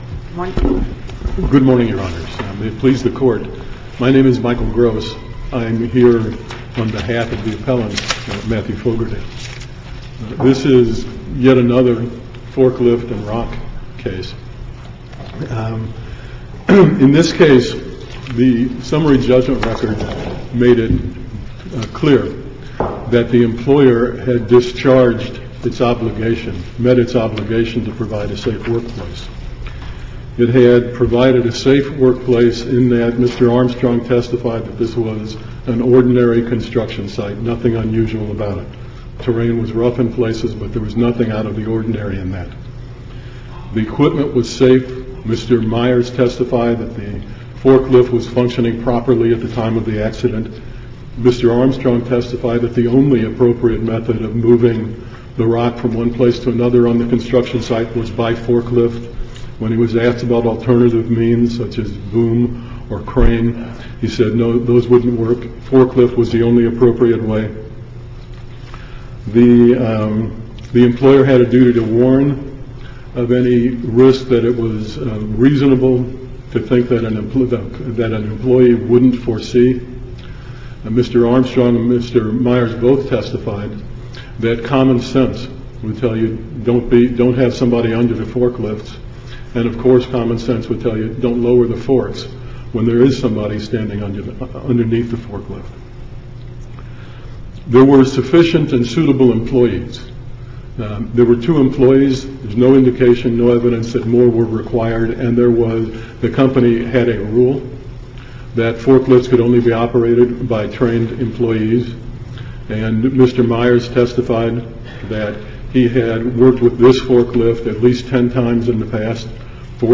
MP3 audio file of oral arguments in SC95995